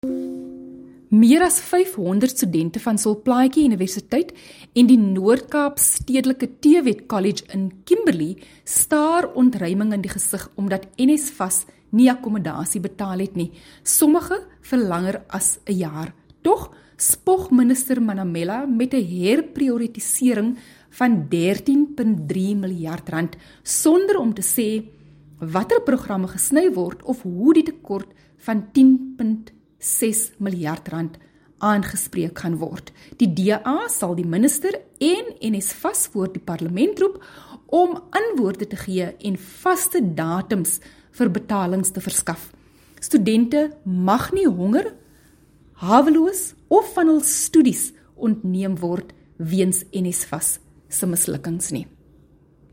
Issued by Dr Delmaine Christians MP – DA Spokesperson on Higher Education & Training
Afrikaans soundbites by Dr Delmaine Christians MP.